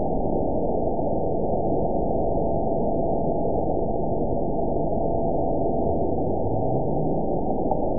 event 911071 date 02/09/22 time 17:11:37 GMT (3 years, 3 months ago) score 9.38 location TSS-AB05 detected by nrw target species NRW annotations +NRW Spectrogram: Frequency (kHz) vs. Time (s) audio not available .wav